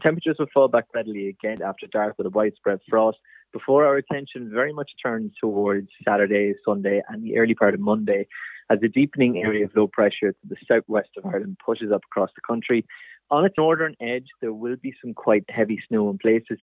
Climate scientist